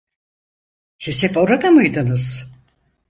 Pronúnciase como (IPA)
/hep/